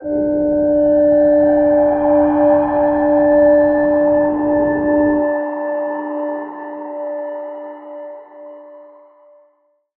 G_Crystal-D5-f.wav